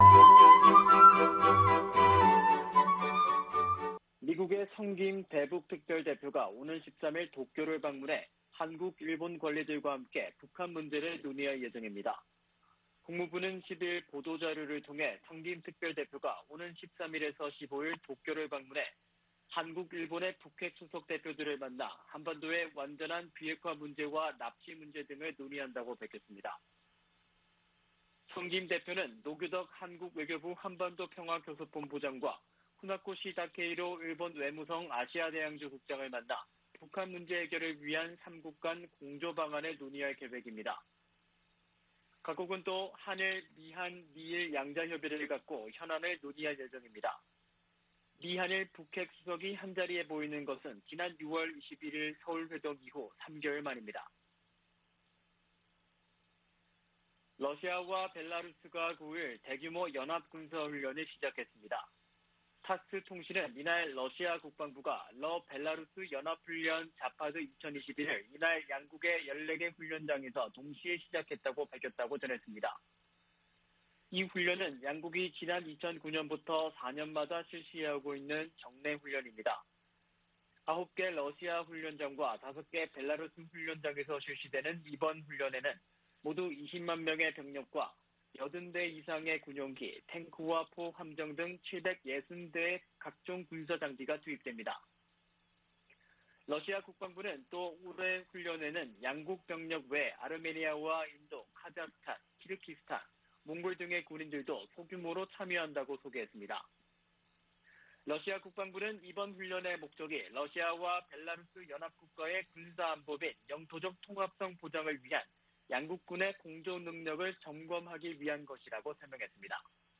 VOA 한국어 아침 뉴스 프로그램 '워싱턴 뉴스 광장' 2021년 9월 11일 방송입니다. 미국 정부는 한반도의 완전한 비핵화가 여전히 목표이며, 이를 위해 북한과의 외교가 중요하다고 밝혔습니다. 북한의 정권수립 73주년 열병식은 군사 보다는 경제 분야에 집중됐다고 미국의 전문가들이 분석했습니다.